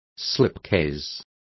Complete with pronunciation of the translation of slipcases.